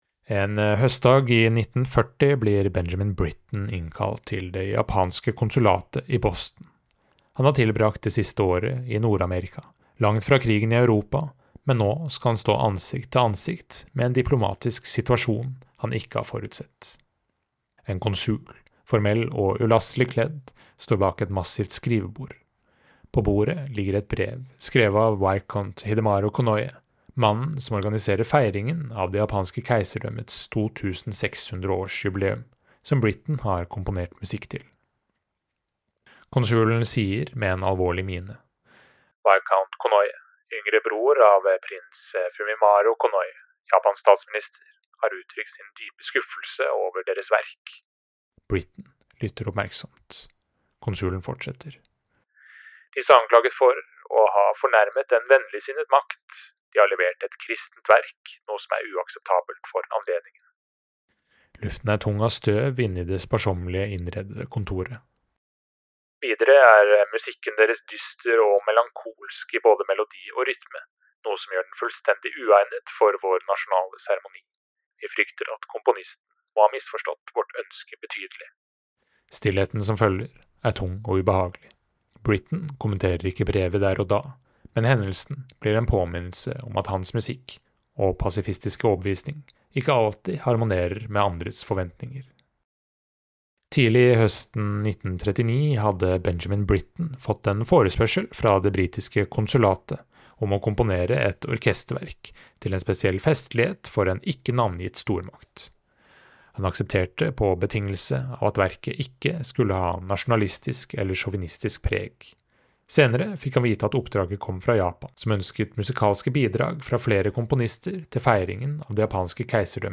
VERKOMTALE